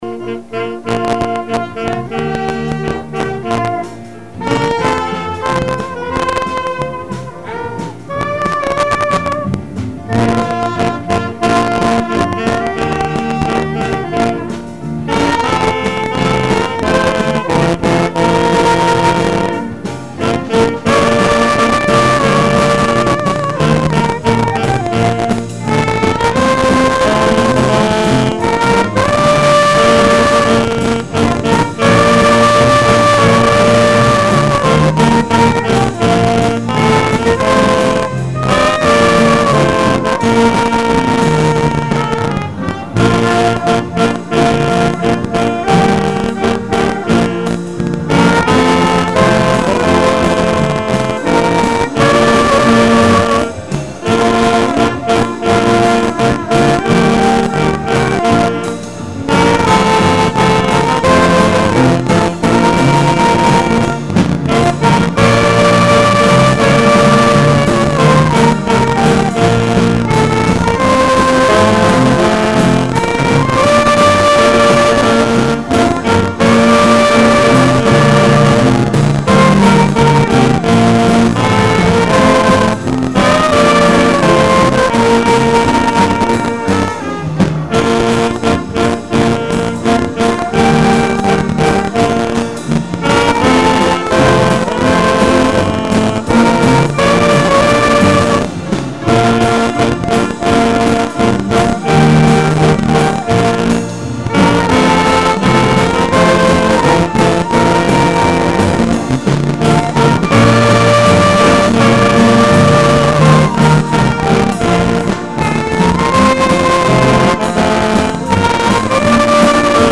02/17/10 Wednesday Service
Band: There is Power in the Blood